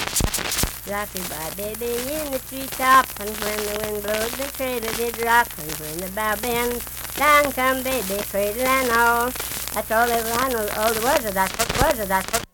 Unaccompanied vocal music
Children's Songs
Voice (sung)
Logan County (W. Va.), Lundale (W. Va.)